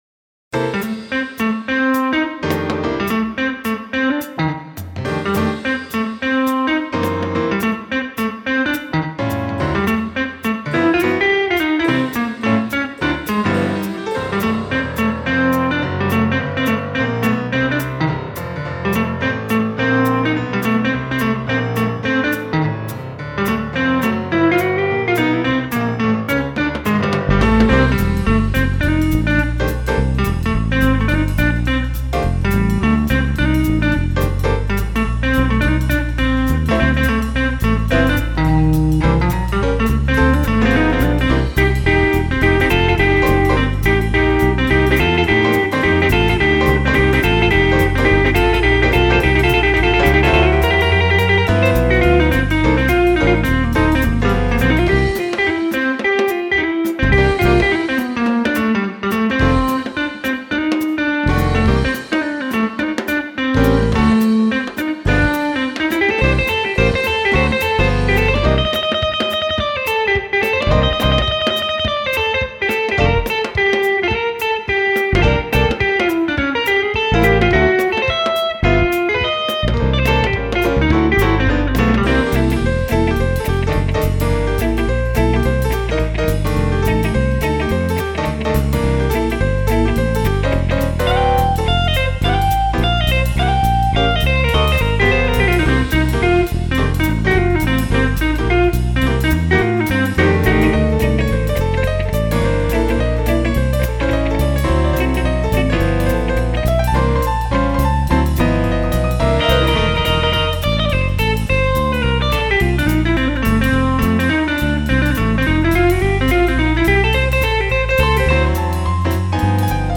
Hollowbody blues electric guitar for Kontakt